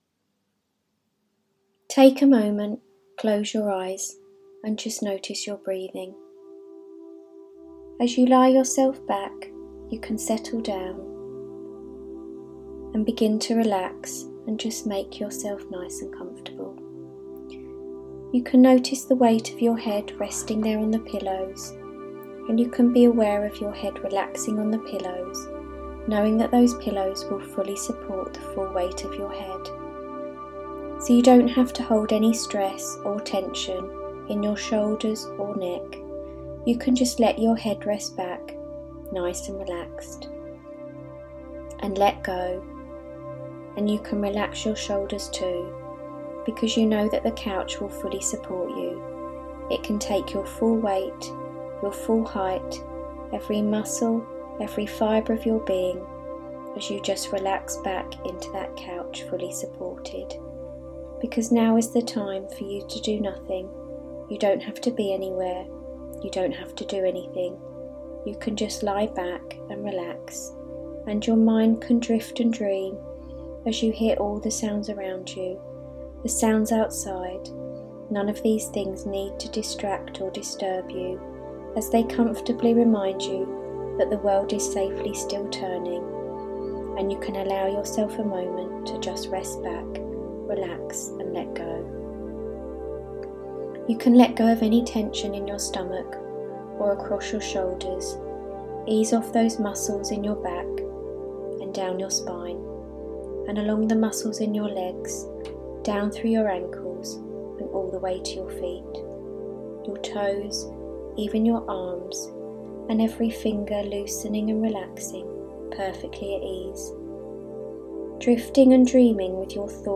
Relaxation Practice Audio Hypnosis is a natural, relaxed state where your mind becomes calmer and more focused, similar to daydreaming or being deeply absorbed in a book or film.